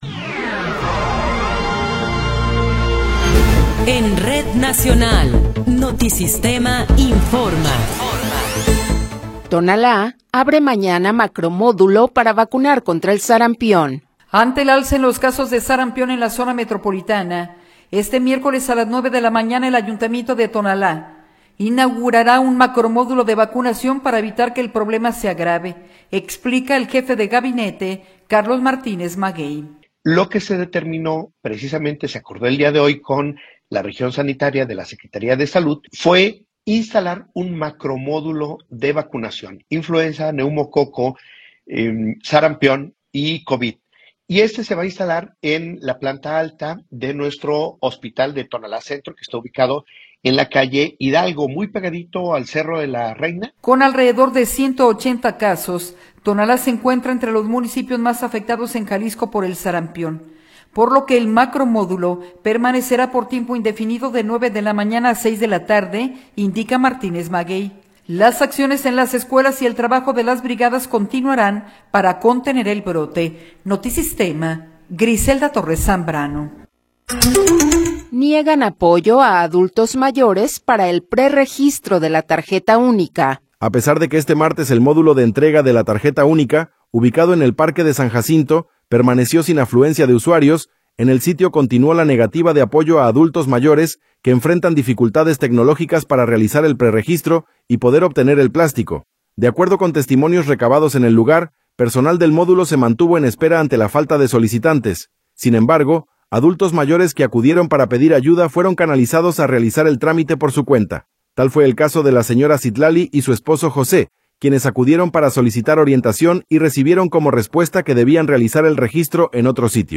Noticiero 15 hrs. – 20 de Enero de 2026
Resumen informativo Notisistema, la mejor y más completa información cada hora en la hora.